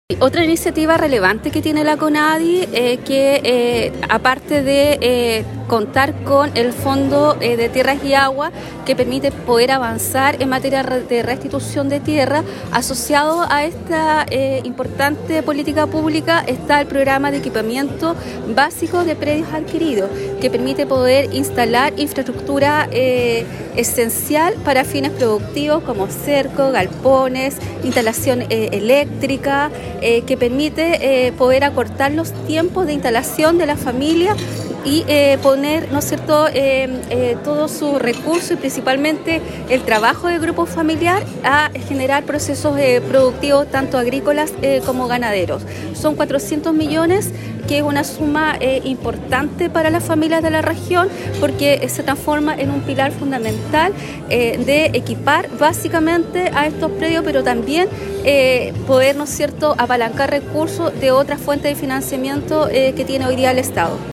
Del mismo modo la Delegada Presidencial enfatizó en la importancia del Programa de Equipamiento Básico de Predios Adquiridos, que permite poder instalar infraestructura esencial para fines productivos, con una inversión cercana a los  400 millones de pesos.